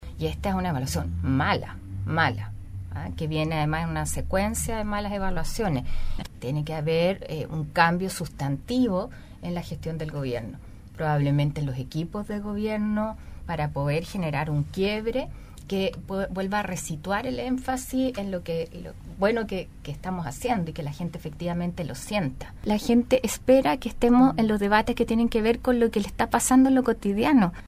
En conversación en el programa Haciendo Ciudad de Radio Sago, la parlamentaria, fue enfática en señalar que la actual administración debe reflexionar en torno a este resultado y apostar por un giro al acercamiento a la comunidad en temas cotidianos.